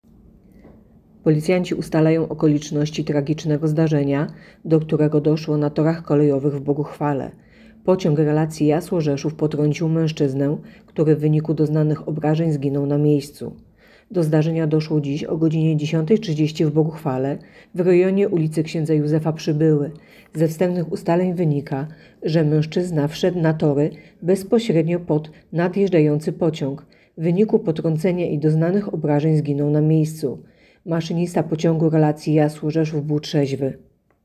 Opis nagrania: Nagranie informacji pt. Tragiczny wypadek na torach.